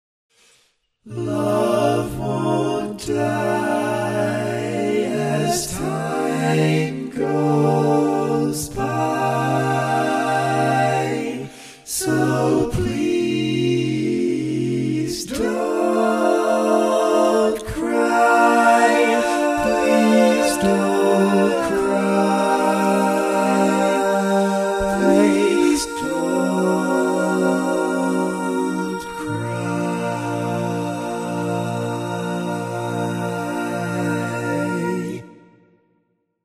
Key written in: B♭ Major
How many parts: 4
Type: Barbershop
Tenor melody.
All Parts mix:
Learning tracks sung by